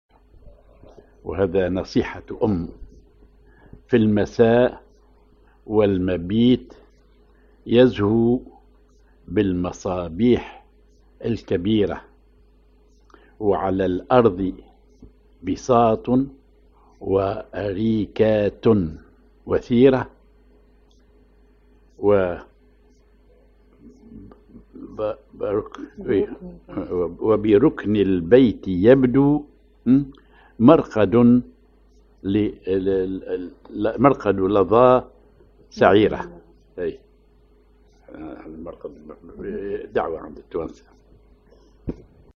Maqam ar حجاز كار كردي
genre نشيد